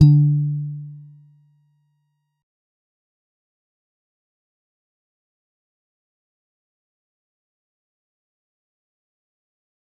G_Musicbox-D3-mf.wav